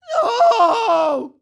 Male_Falling_Shout_06.wav